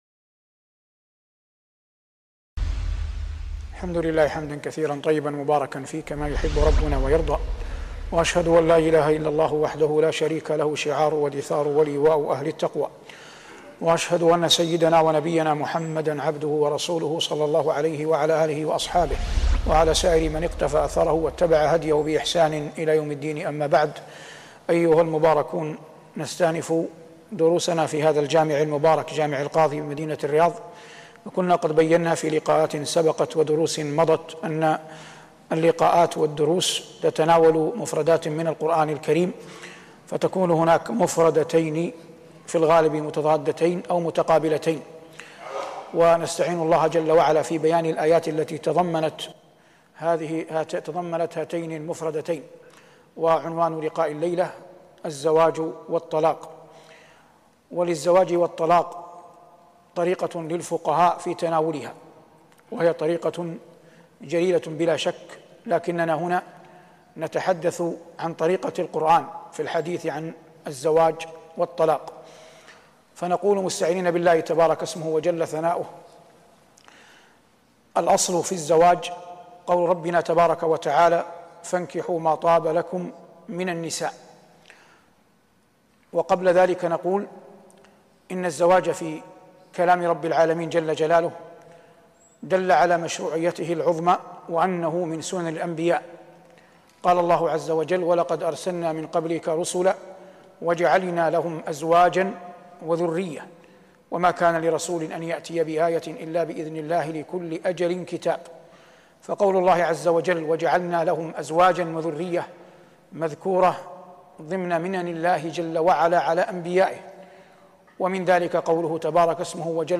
شبكة المعرفة الإسلامية | الدروس | الزواج والطلاق -1 |صالح بن عواد المغامسي